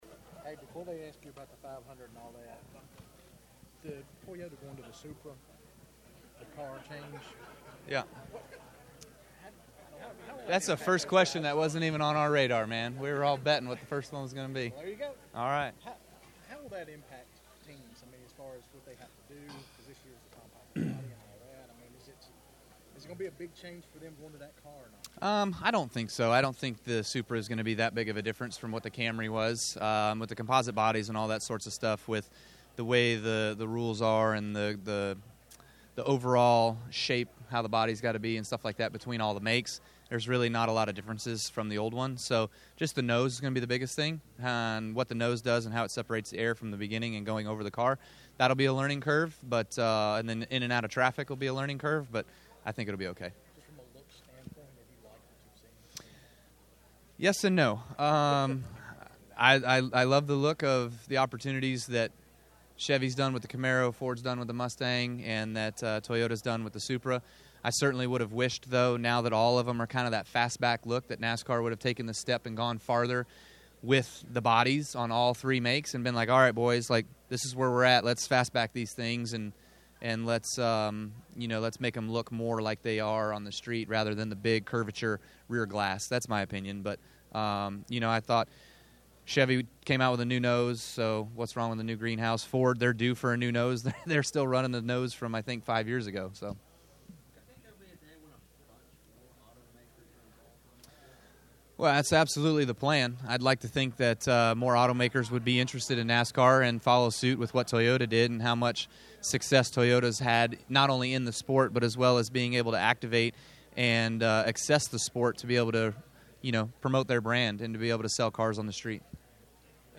Kyle Busch speaks to the media during the Monster Energy NASCAR Cup Series 61st Annual Daytona 500 Media Day at Daytona International Speedway on February 13, 2019 in Daytona Beach, Florida.